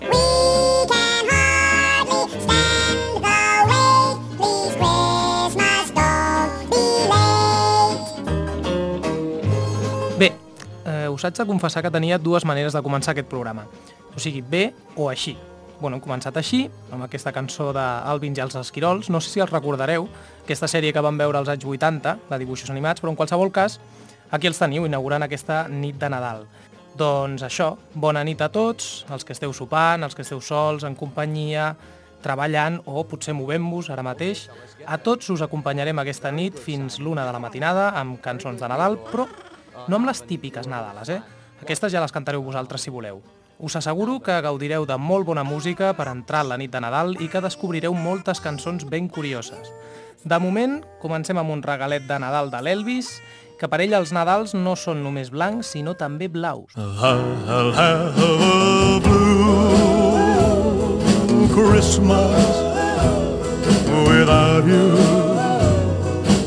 Inici del programa musical amb cançons de Nadal
Musical